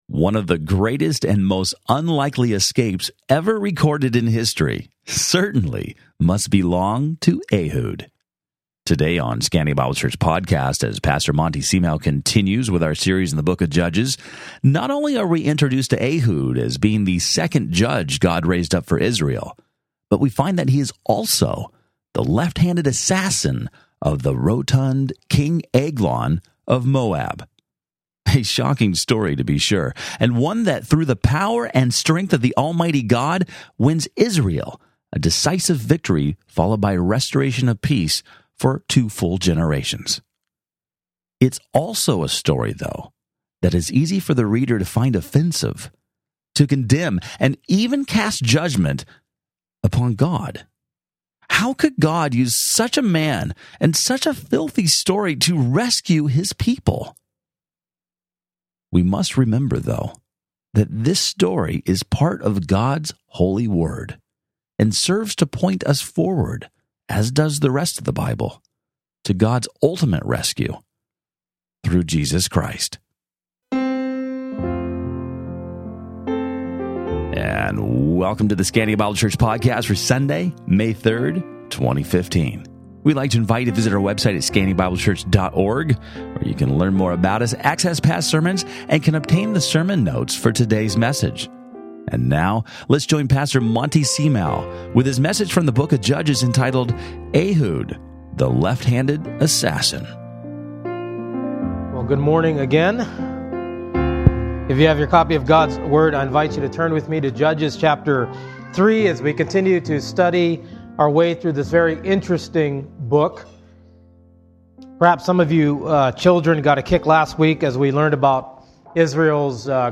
Listen to Sermon Only
Date: 04/12/2020, Easter Sunday